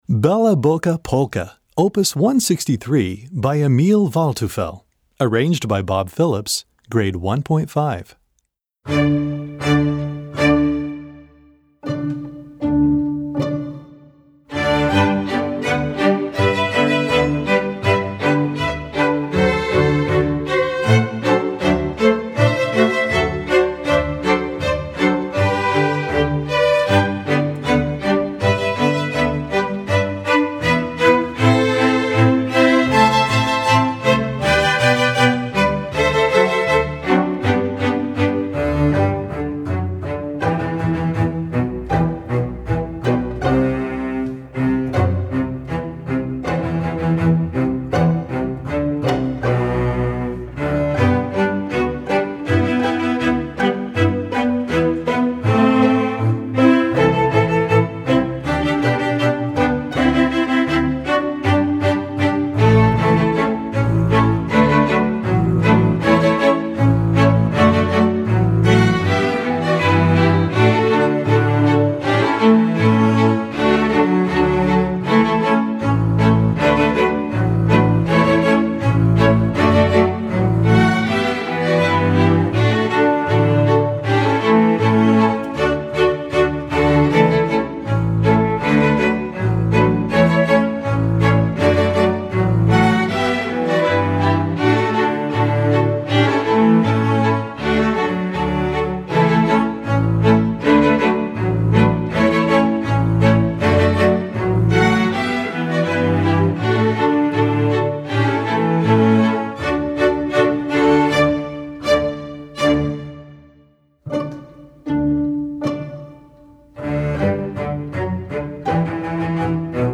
Gattung: Polka
Besetzung: Streichorchester